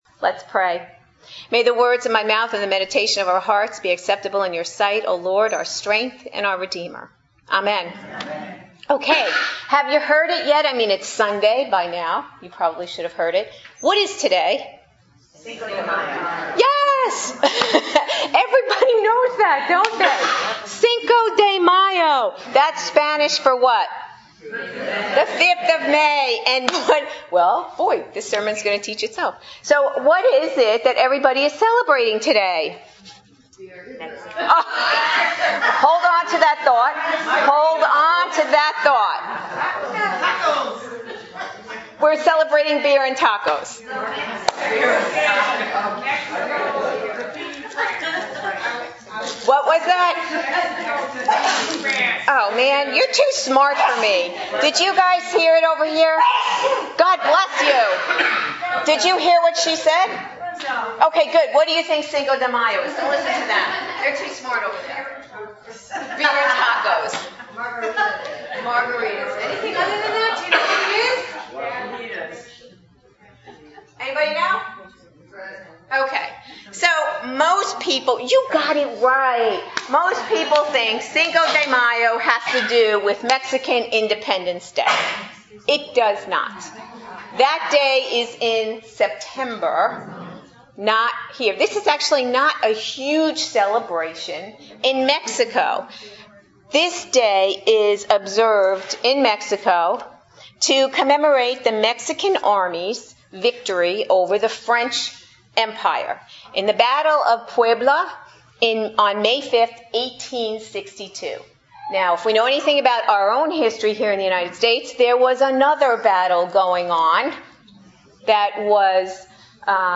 Adult Sermons